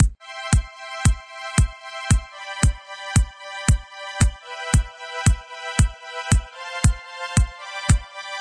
[パターン２]：裏拍・・・
次に [Dest Min.] と [Dest Max.] の値を反転し、キックドラムが鳴った時にはシンセ音が抑えられる（消える）ようにしました。 正確に裏拍で鳴るようにするのはちょっと難しかったんですが、シンセが「ゥワー・ゥワー・ゥワー・ゥワー」と鳴る雰囲気を感じてください。
Side-chain-test-2.mp3